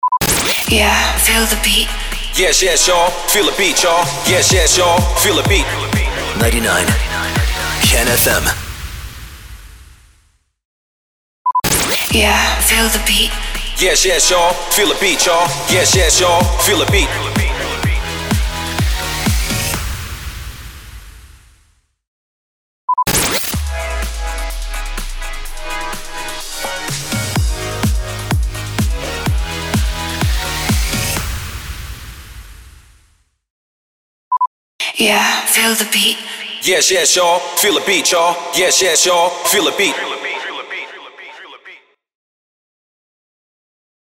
767 – SWEEPER – FEEL THE BEAT